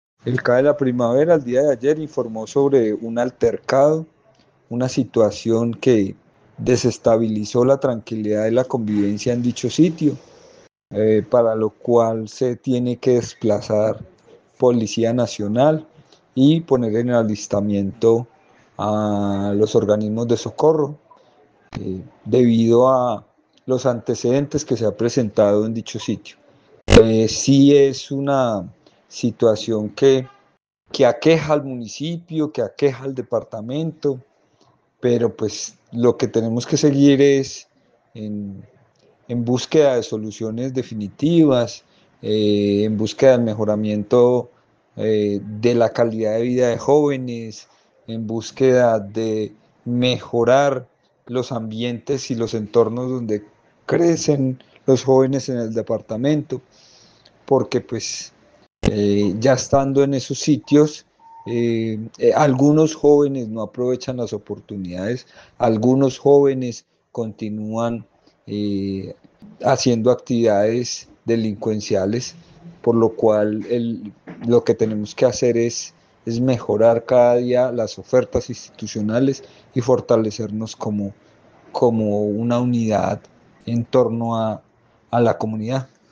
Secretario de Gobierno de Montenegro